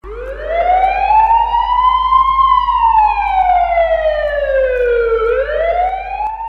Tiếng Còi Xe Cấp Cứu (Nhạc Chuông)